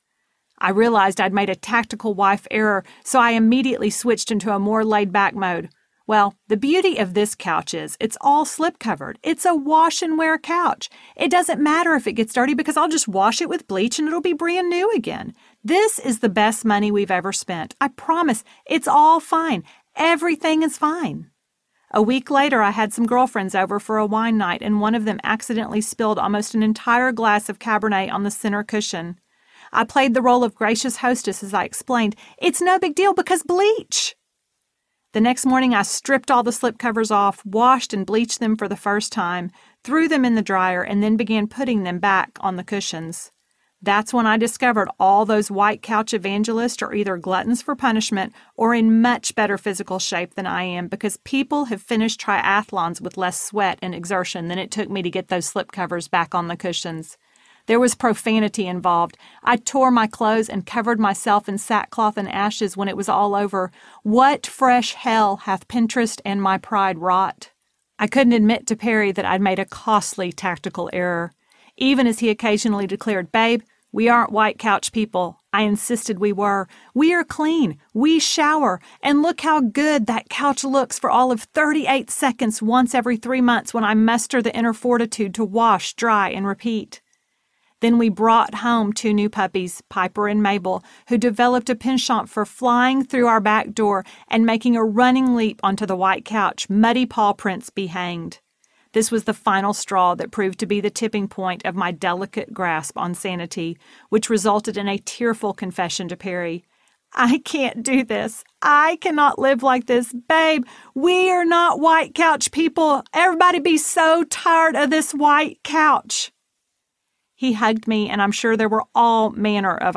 Church of the Small Things Audiobook
5.27 Hrs. – Unabridged